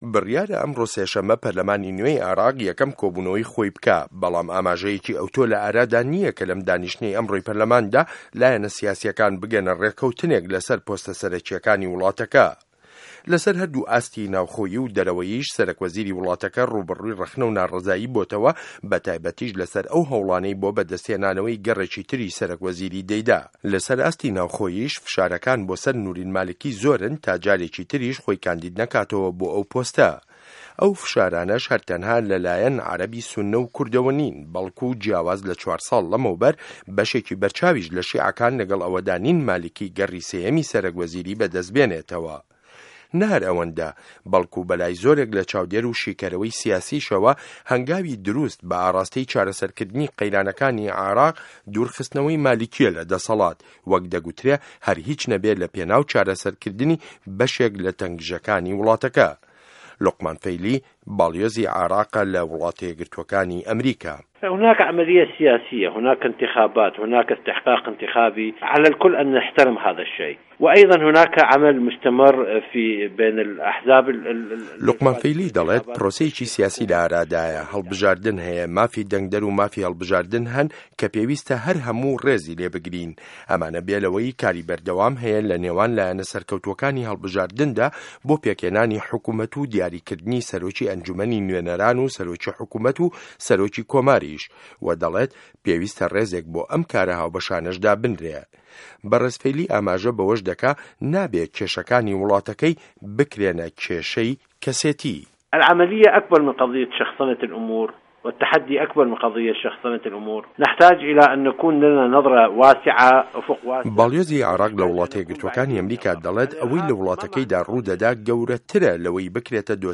ڕاپۆرت له‌سه‌ر بنچینه‌ی لێدوانه‌کانی باڵیۆزی عێراق له‌ وڵاته‌ یه‌کگرتووه‌کانی ئه‌مریکا